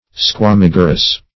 Definition of squamigerous.
Search Result for " squamigerous" : The Collaborative International Dictionary of English v.0.48: Squamigerous \Squa*mig"er*ous\ (skw[.a]*m[i^]j"[~e]r*[u^]s), a. [L. squamiger; squama a scale + gerere to bear.]